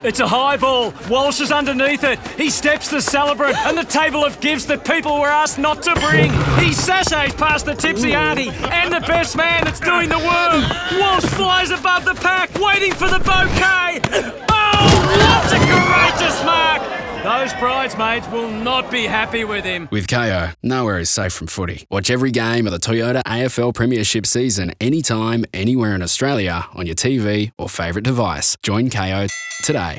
The radio execution narrates Walsh running through a wedding to mark the bouquet toss, and Keary dodging disgruntled shoppers at a furniture store (that has Swedish meatballs) to dive into a double bed.
Kayo-Radio-Nowhere-is-safe-from-footy-AFL-Wedding-.wav